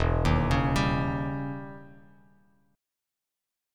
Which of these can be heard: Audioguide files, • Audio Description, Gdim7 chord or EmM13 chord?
EmM13 chord